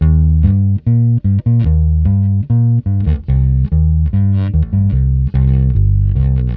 Twisting 2Nite 4 Bass-D.wav